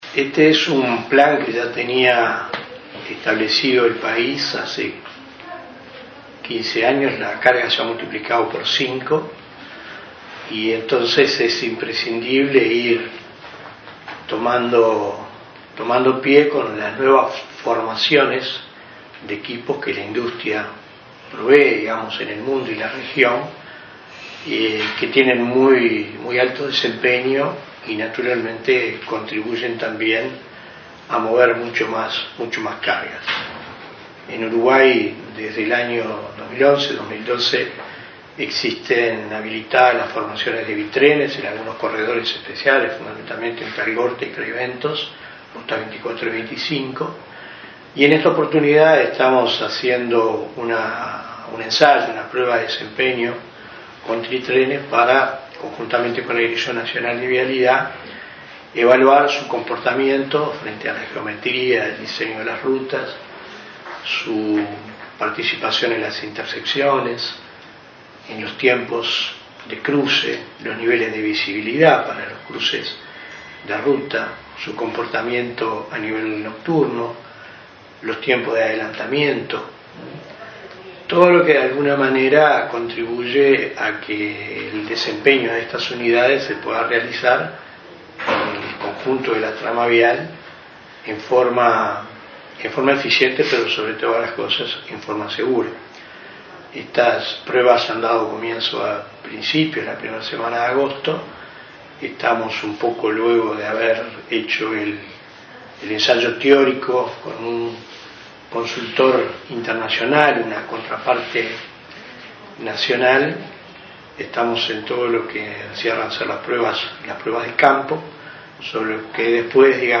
“En 15 años la carga que se transporta en Uruguay se multiplicó por cinco”, señaló el director nacional de Transporte, Felipe Martín, al informar de las pruebas que se realizan con camiones tritrenes.